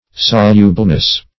Solubleness \Sol"u*ble*ness\, n.